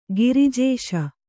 ♪ girijēśa